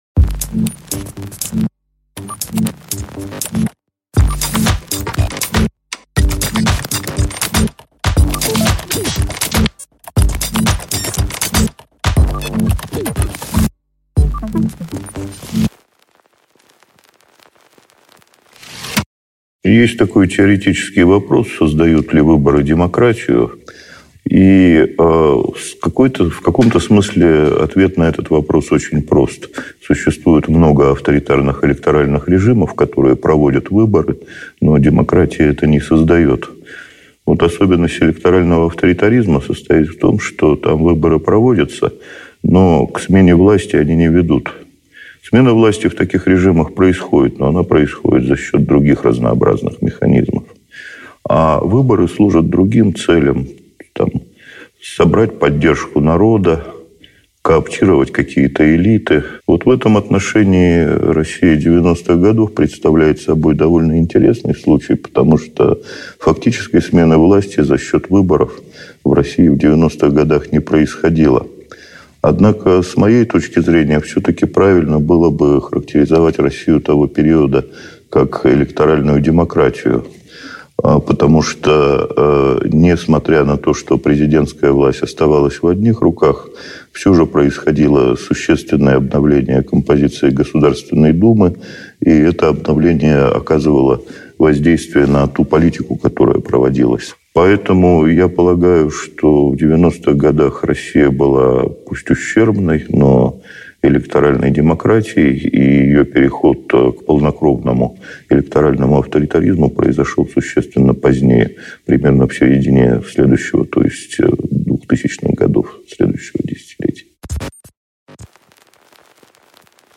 Аудиокнига Особенности постсоветской электоральной демократии | Библиотека аудиокниг